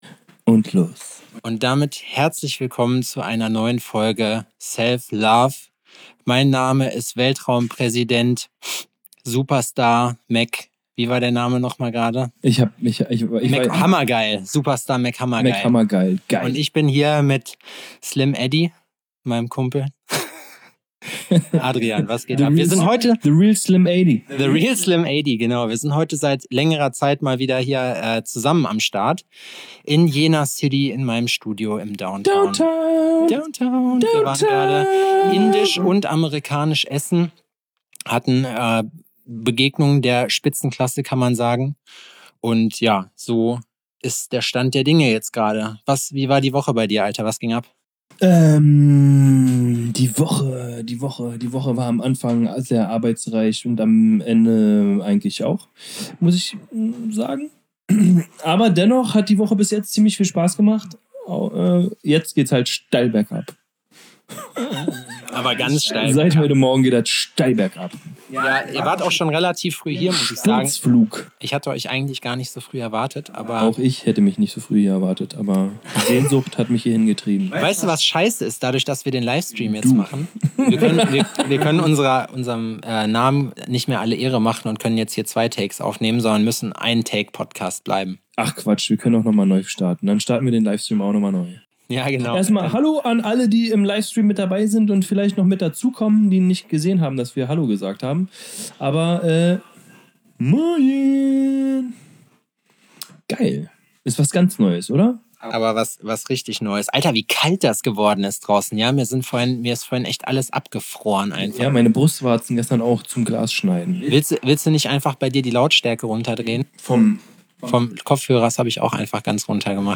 Wir haben uns mal wieder in Jena getroffen und die Aufnahme parallel auf Instagram gestreamt uns uns in gewohnter Manier parralel noch einen reingestellt. Konsequente Verballertheit, so wie ihr es gewohnt seid, es wird gelacht, es wird geweint.